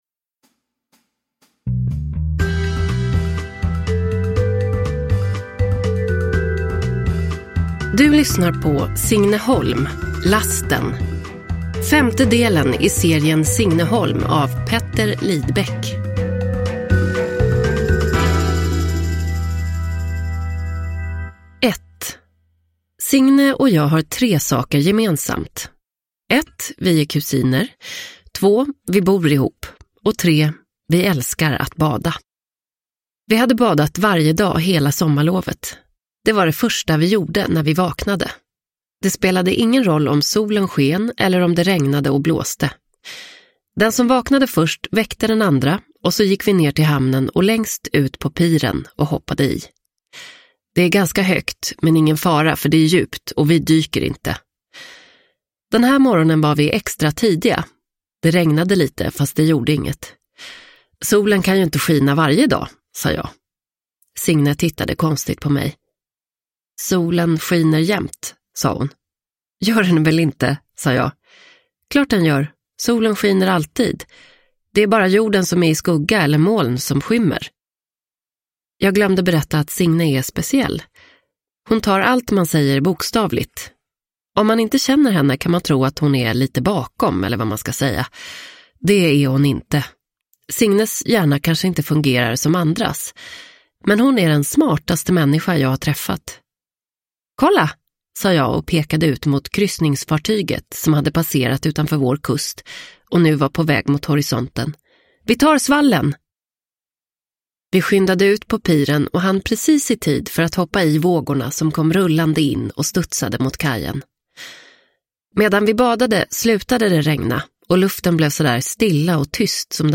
Lasten – Ljudbok – Laddas ner